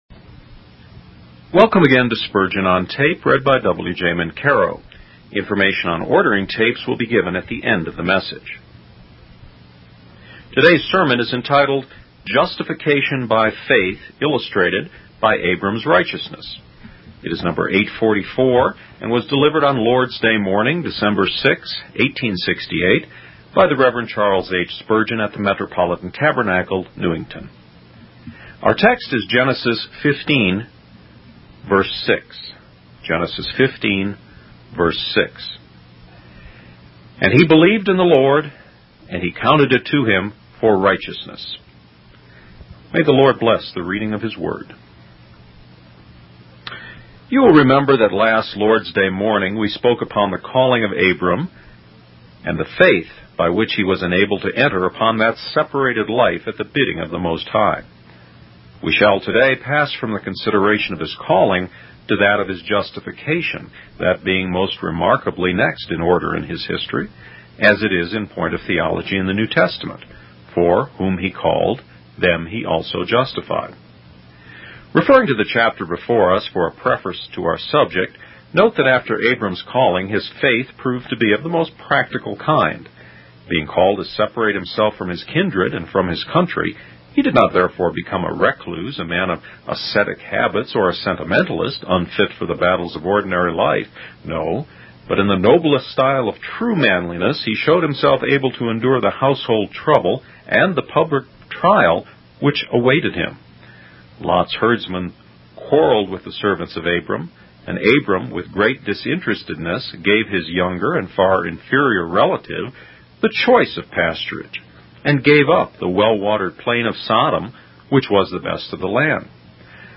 In this sermon, the preacher discusses the importance of understanding that entering into a relationship with God does not mean an end to troubles and challenges. He uses the example of Abraham to illustrate this point.